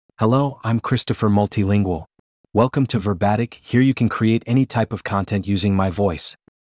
MaleEnglish (United States)
Christopher Multilingual is a male AI voice for English (United States).
Voice sample
Listen to Christopher Multilingual's male English voice.
Christopher Multilingual delivers clear pronunciation with authentic United States English intonation, making your content sound professionally produced.